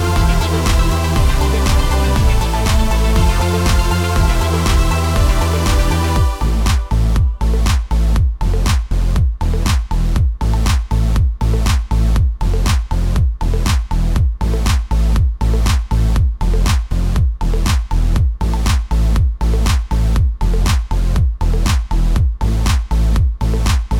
For Solo Female